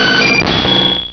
sovereignx/sound/direct_sound_samples/cries/skarmory.aif at 2f4dc1996ca5afdc9a8581b47a81b8aed510c3a8